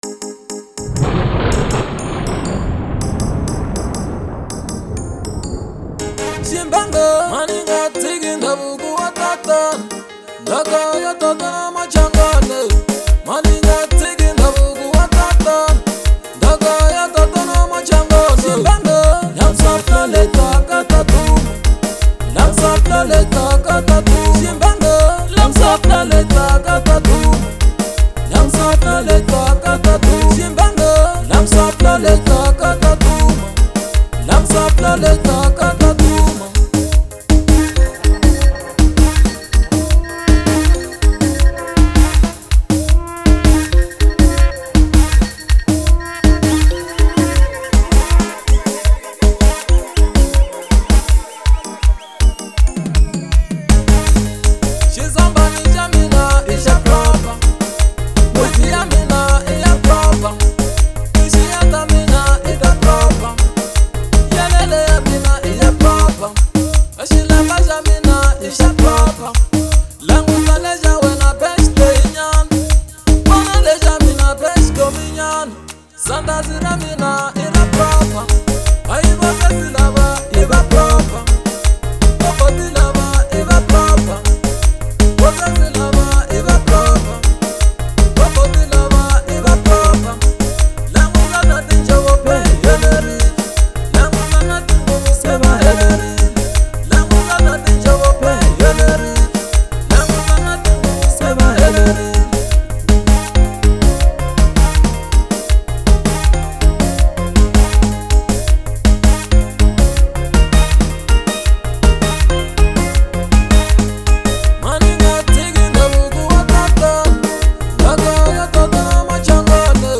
04:08 Genre : Xitsonga Size